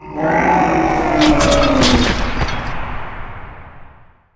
SFX怪物低沉的吼叫－铁器碰撞音效下载
SFX音效